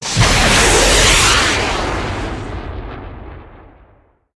missileTakeOff.ogg